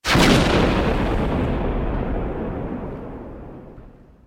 Explosion 3